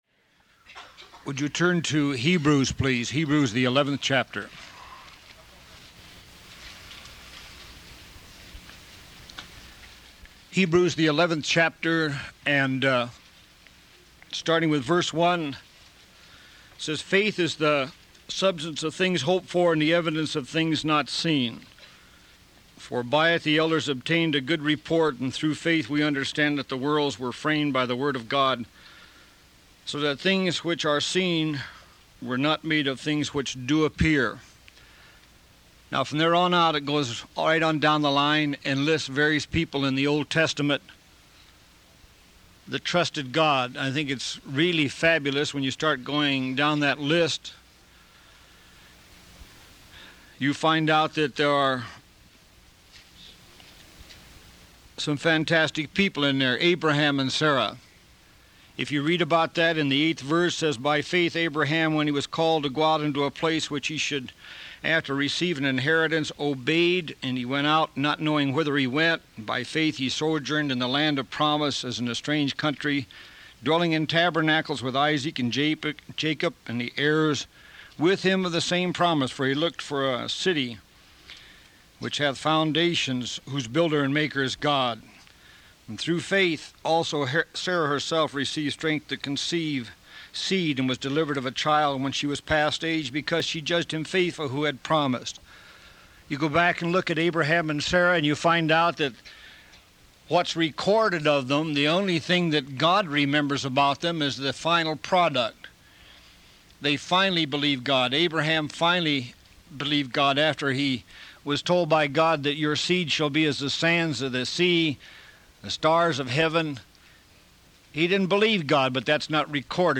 Faith - Hebrews 11 download sermon mp3 download sermon notes Welcome to Calvary Chapel Knoxville!